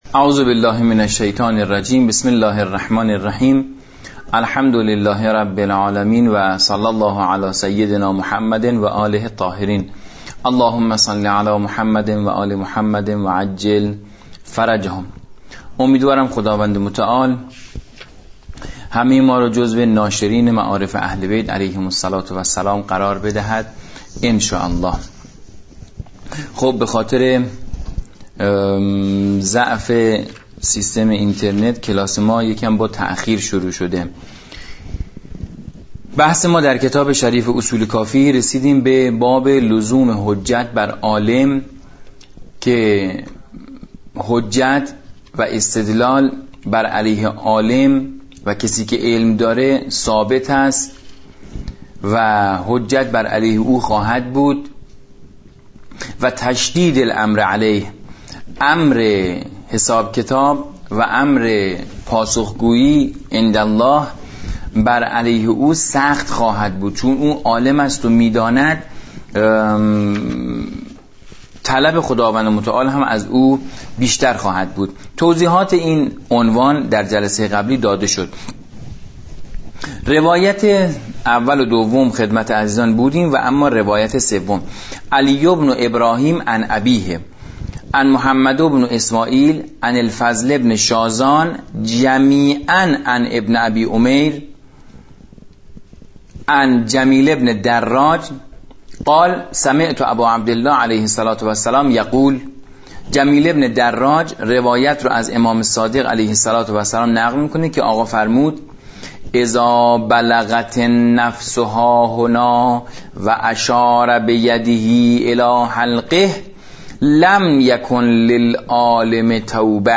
حدیث خوانی اصول کافی